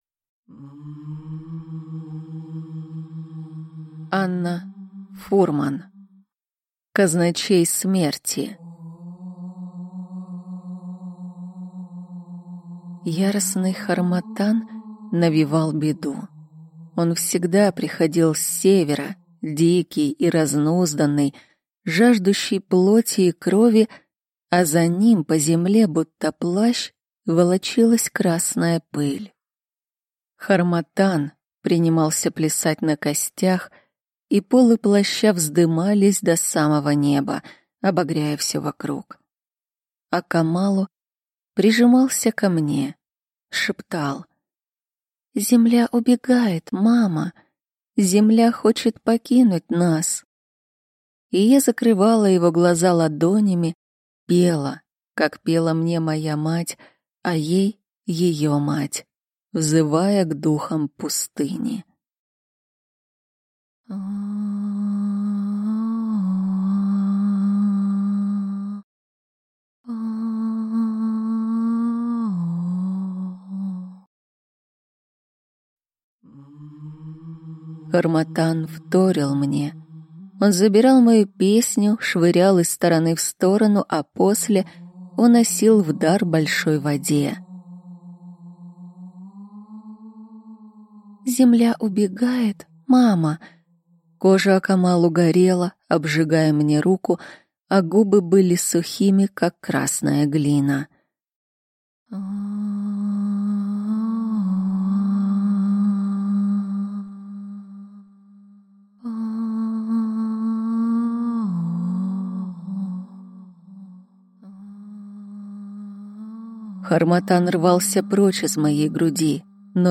Аудиокнига Казначей смерти | Библиотека аудиокниг